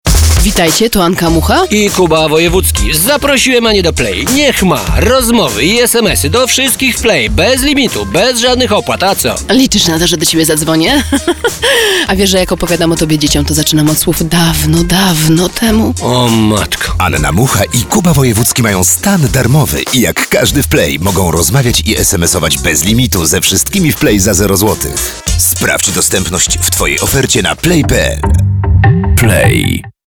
RADIO: